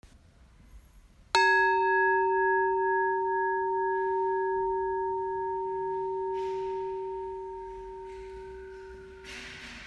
Przyporządkujcie numerki do właściwych dźwięków: łamanie hostii, przewracanie stron w Mszale, zamykanie drzwi w kościele, dzwonki, wlewanie wody do kielicha, otwieranie drzwi do konfesjonału, gong, machanie kadzidłem.